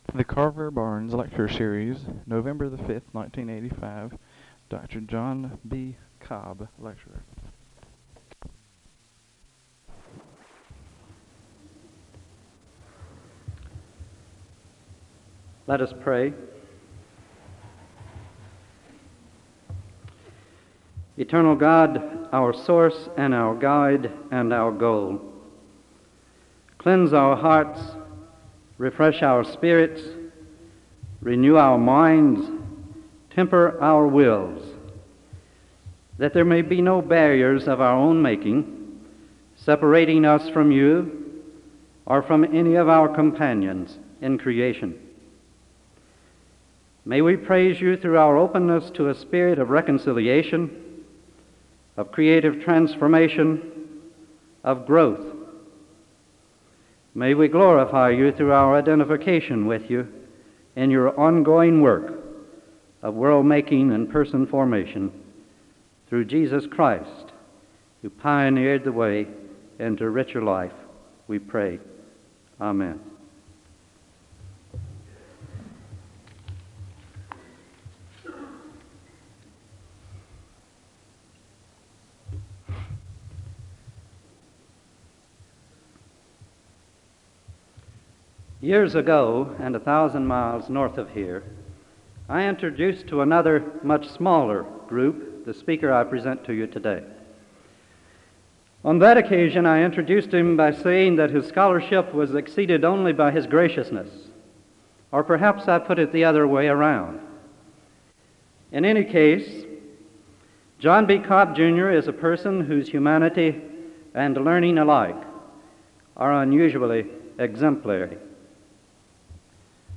File Set | SEBTS_Carver-Barnes_Lecture_John_B_Cobb_1985-11-05.wav | ID: 3cfb92ac-47de-490b-acb3-baeb051bfaa6 | Hyrax